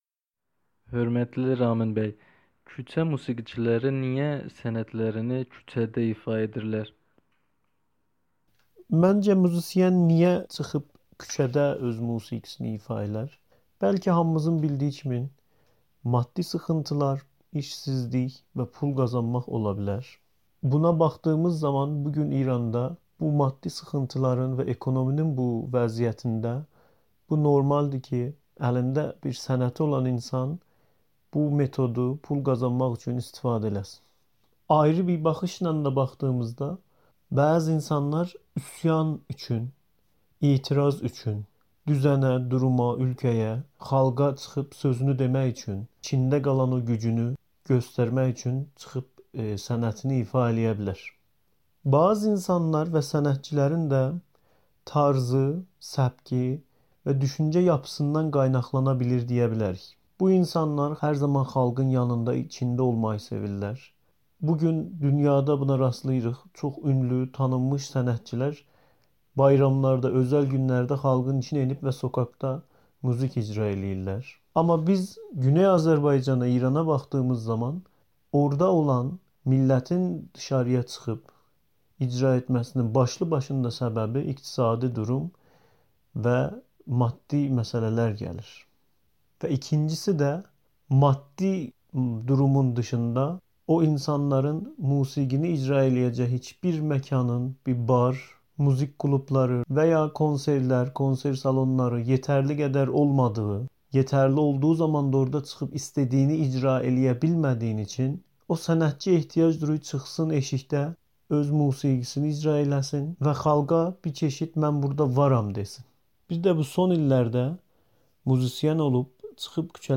Təbriz küçələrində Azərbaycan xalq musiqisi ifa olunur
ABŞ sanksiyaları ilə üzləşən İranda iqtisadi durumun pisləşməsi fonunda Təbriz şəhərində küçə musiqiçilərinin ifaları şəhərə başqa bir rəng qatır. Yerli əhali canlı musiqi səsini eşidincə musiqiçilərin ətrafına toplaşır, alqışlayırlar və bəzən uşaqlar da musiqi havası ilə oynamağa başlayırlar....